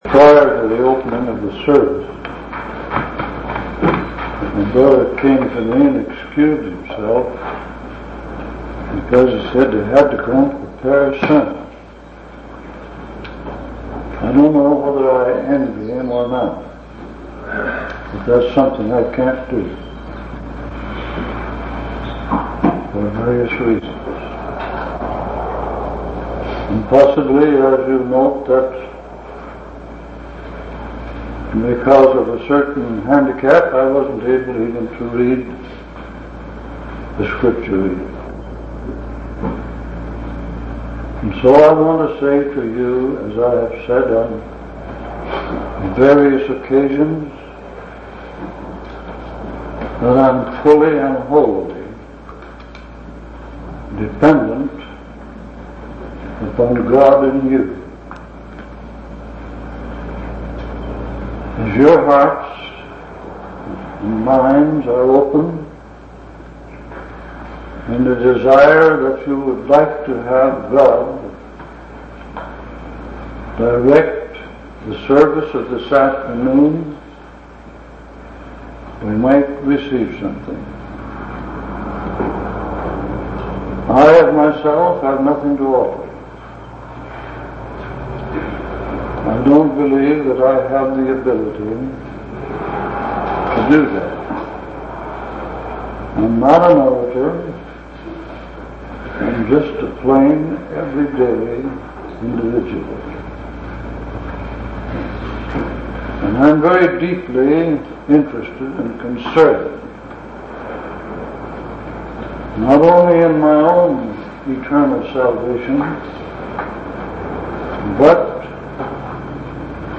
4/12/1964 Location: Temple Lot Local Event